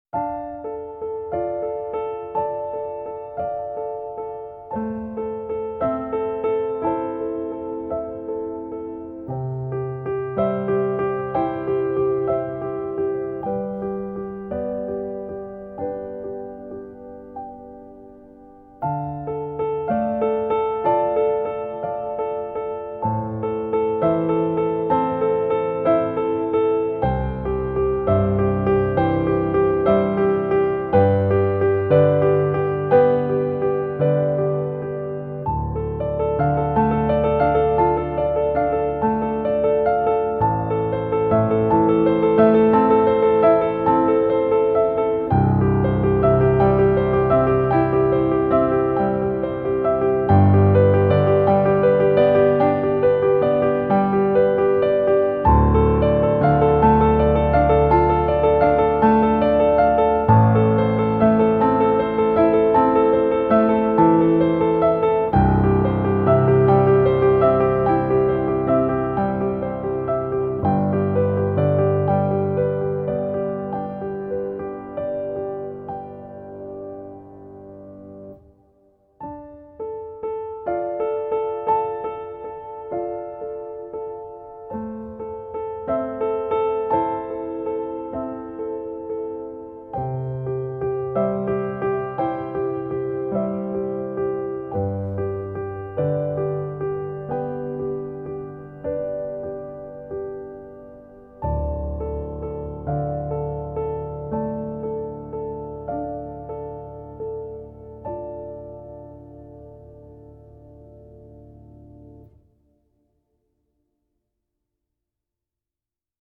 轻松愉悦